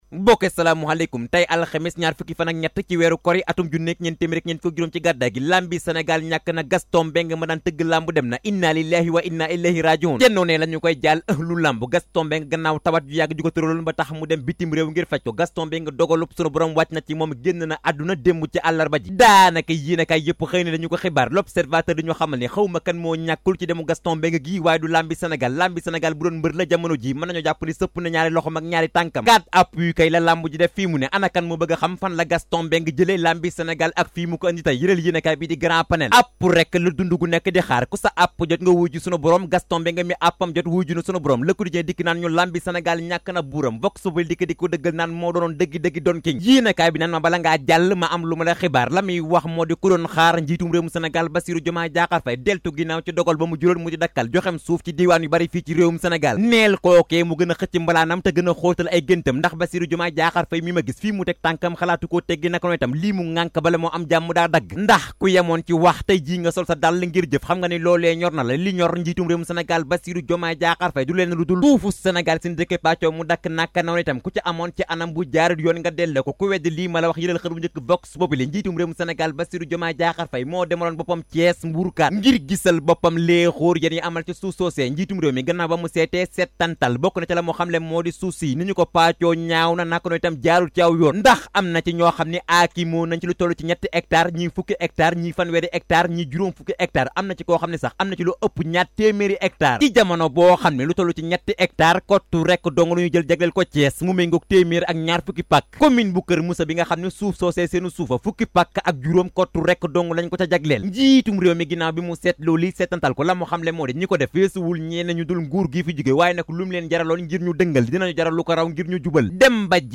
Revue de presse de Leral de ce jeudi 2 mai 2024